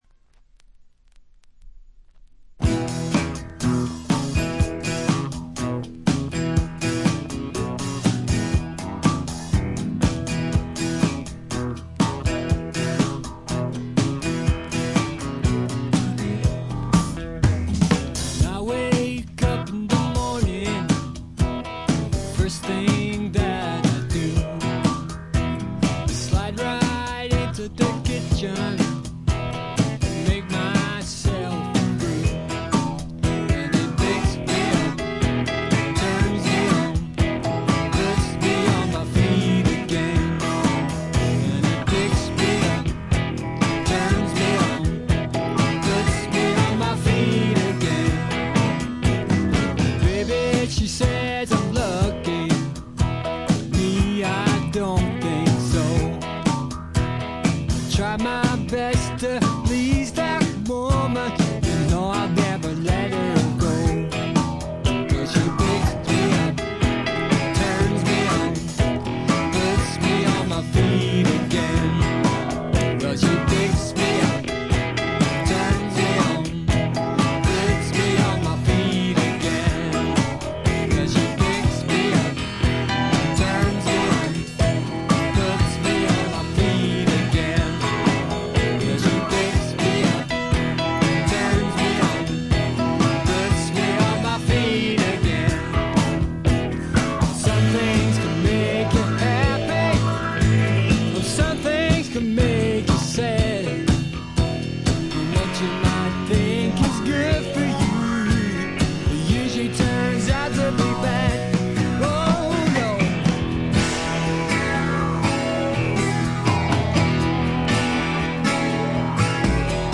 *** LP ： UK 1975
バックグラウンドノイズ、軽微なチリプチが出ていますが、特に気になるノイズはありません。
試聴曲は現品からの取り込み音源です。